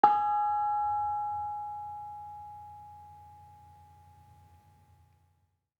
Bonang-G#4-f.wav